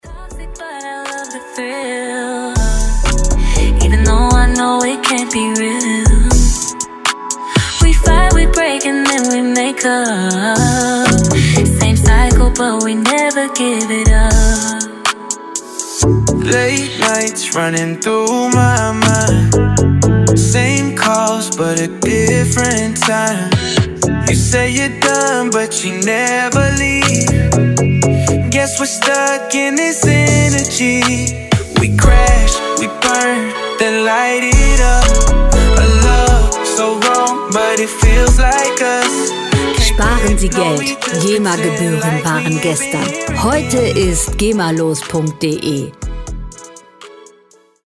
Musikstil: R&B
Tempo: 120 bpm
Tonart: Fis-Moll
Charakter: verspielt, verführerisch
Instrumentierung: Synthesizer, R&B Duett